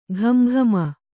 ♪ ghama ghama